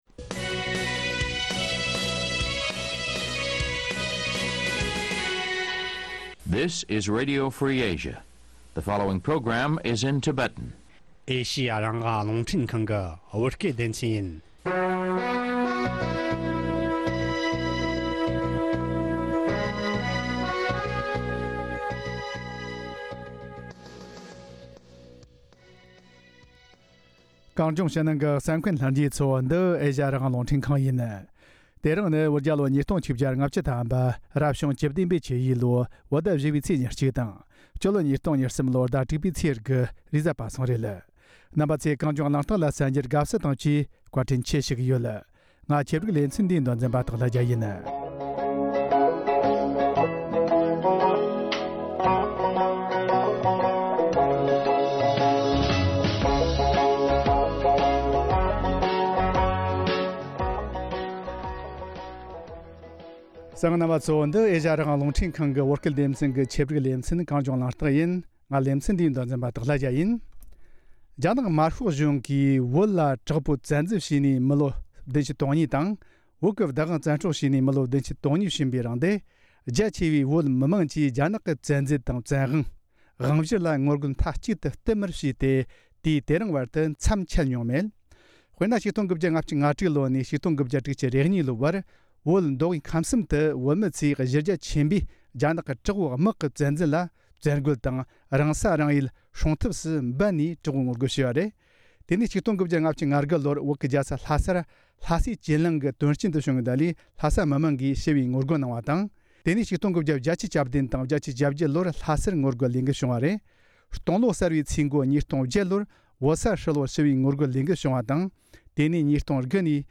ལས་འགུལ་སྤེལ་བའི་འདས་པའི་ཟླ་ཤས་རིང་གི་མྱོང་ཚོར་སོགས་ཁོང་ལ་བཅར་འདྲི་ཞུས་པ་དང་།